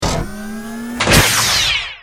battlesuit_tinylaser.ogg